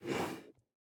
sounds / mob / turtle / idle1.ogg